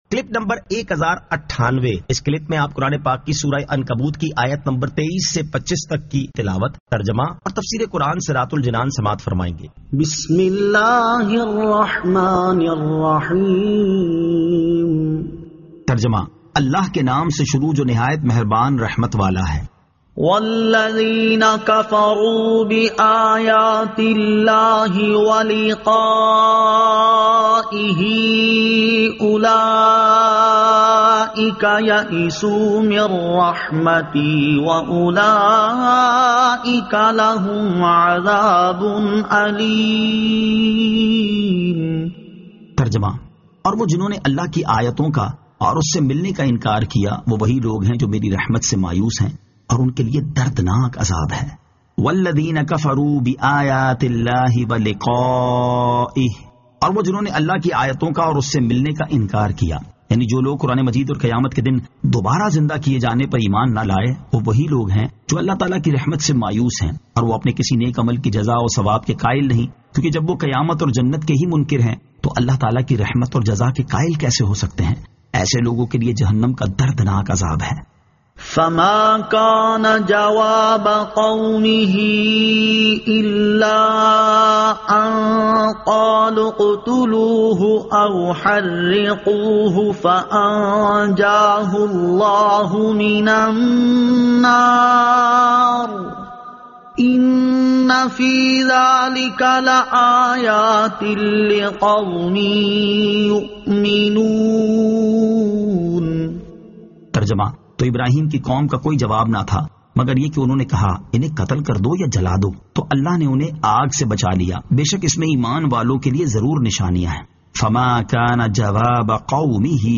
Surah Al-Ankabut 23 To 25 Tilawat , Tarjama , Tafseer